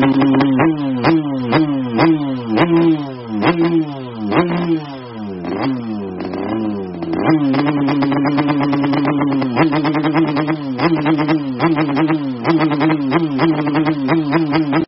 Nada Dering Suara Knalpot Motor Racing
Keterangan: Nada dering suara knalpot motor racing ngeng reng ngeng reng reng... gabut njirrrrr viral di TikTok.
nada-dering-suara-knalpot-motor-racing-id-www_tiengdong_com.mp3